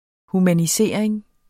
Udtale [ humaniˈseˀɐ̯eŋ ]